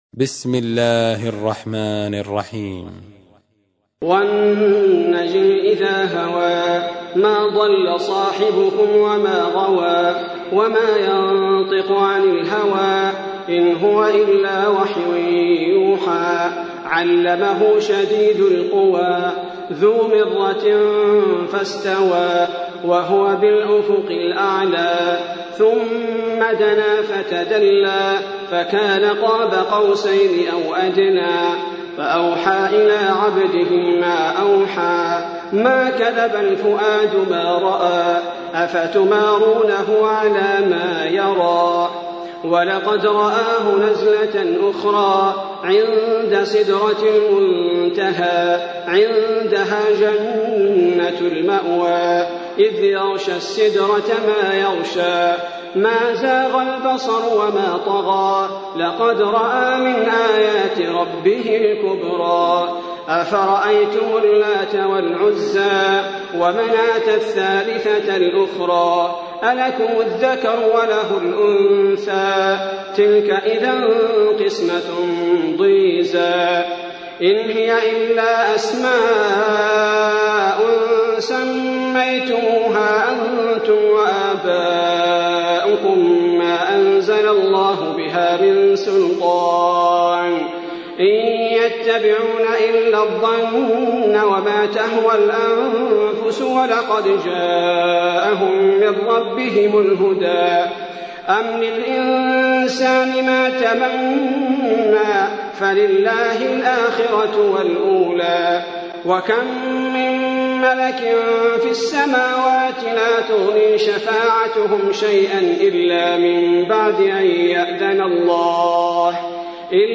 تحميل : 53. سورة النجم / القارئ عبد البارئ الثبيتي / القرآن الكريم / موقع يا حسين